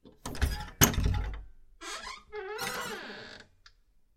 随机的 "木质室内门 "住宅打开关闭螺栓吱吱作响的声音
描述：门木内部住宅开放关闭螺栓吱吱声creak.wav
Tag: 打开 关闭 内部 吱吱 螺栓 木材 住宅